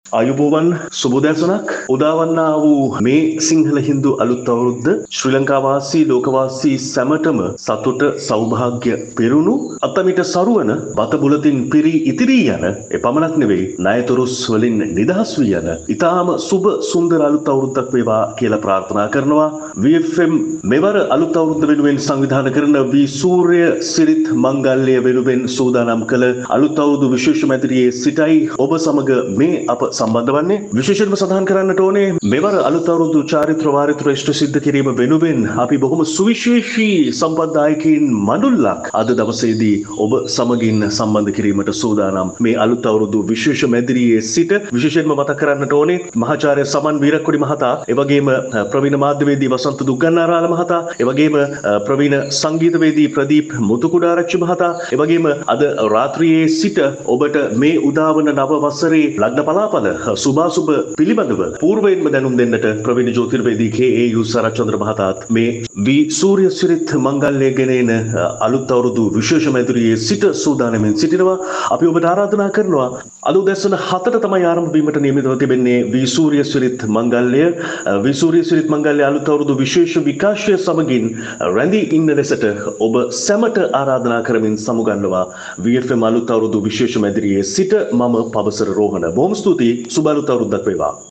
මෙම සජීව විකාශය ගෙන එනු ලබන්නේ ඒ වෙනුවෙන් ම සැකසු විශේෂිත මැදිරියක් තුළ සිටයි.